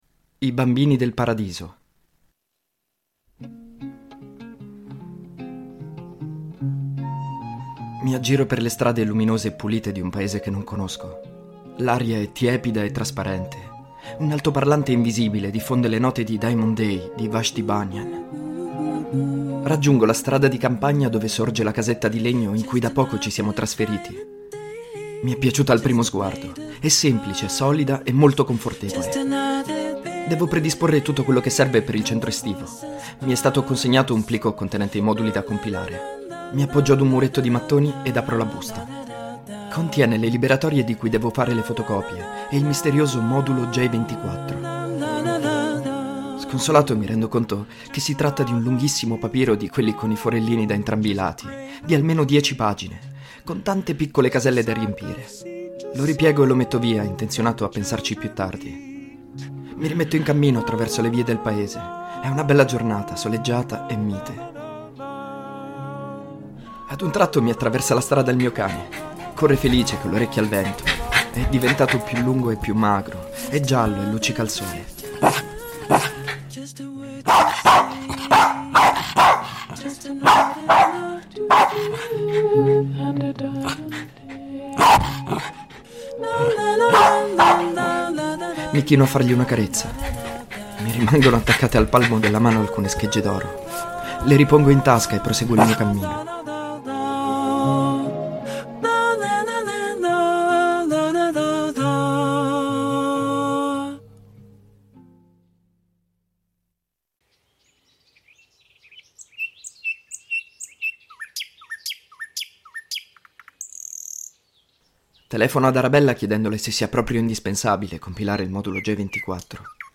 La colonna sonora è costituita da cover o versioni strumentali di "Just Another Diamond Day" di Vashti Bunyan.
The soundtrack consists of covers or instrumental versions of "Just Another Diamond Day" by Vashti Bunyan.